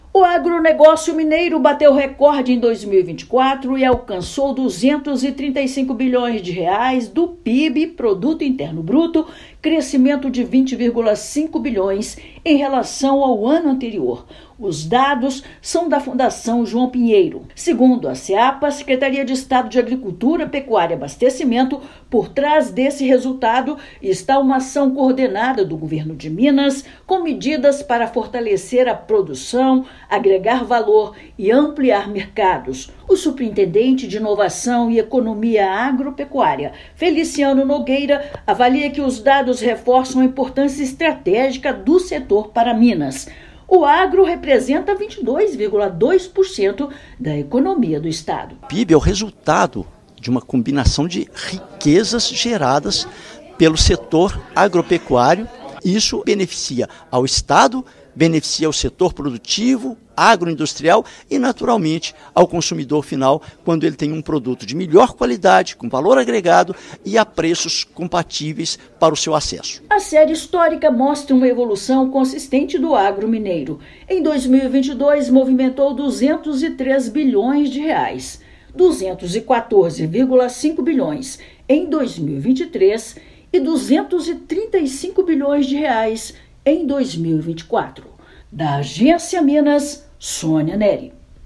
Valor Adicionado Bruto (VAB) no núcleo das atividades agrícolas, da pecuária e da produção florestal evoluiu de R$ 61,8 bilhões em 2023 para R$ 70 bilhões em 2024. Ouça matéria de rádio.